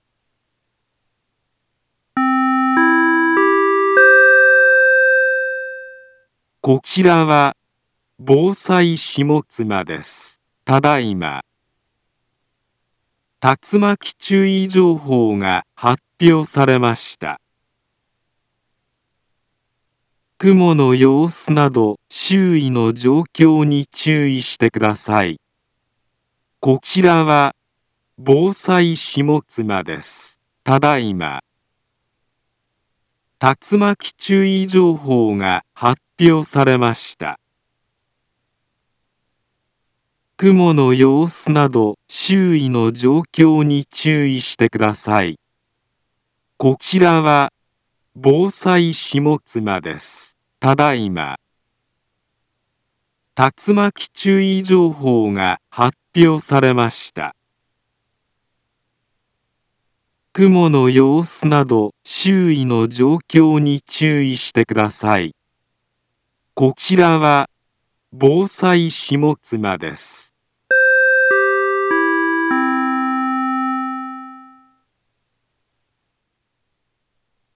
Back Home Ｊアラート情報 音声放送 再生 災害情報 カテゴリ：J-ALERT 登録日時：2025-09-17 20:34:47 インフォメーション：茨城県南部は、竜巻などの激しい突風が発生しやすい気象状況になっています。